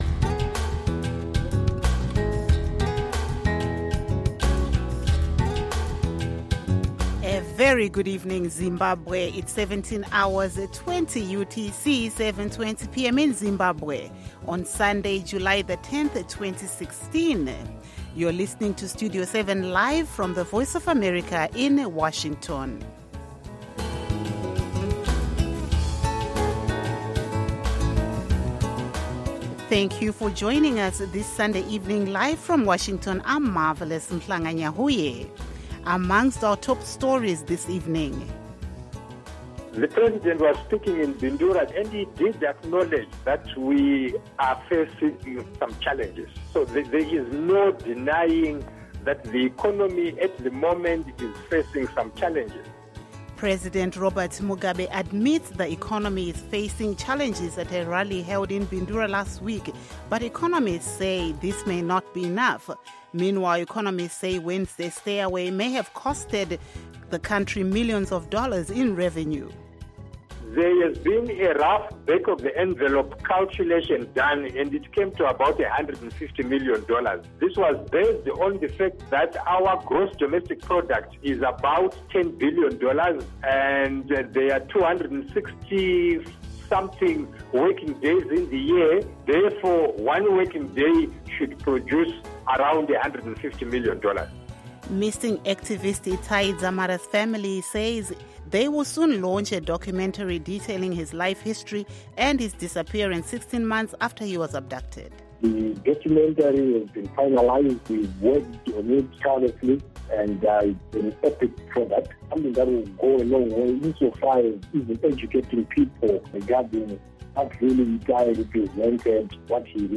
Studio 7 News in English